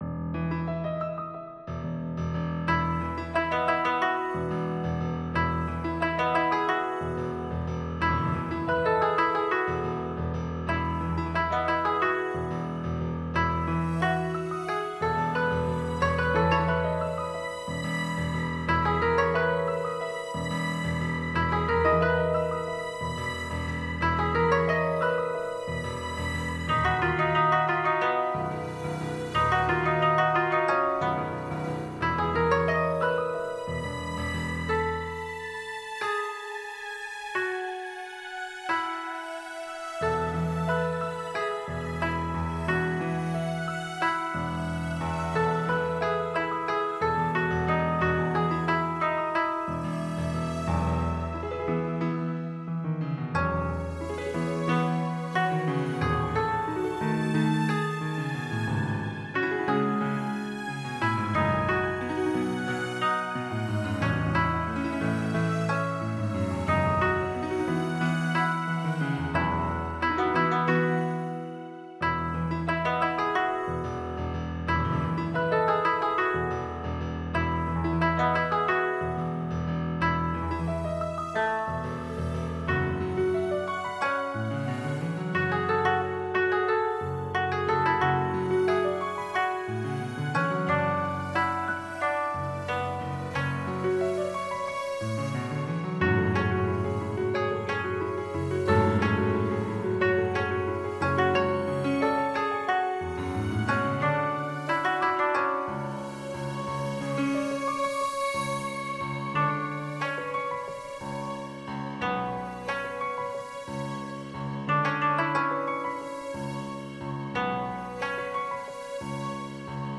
I composed this piece for the Japanese harp koto and piano, and it can be easily rearranged for a string instrument and piano.
Light-and-shadows-oriental.mp3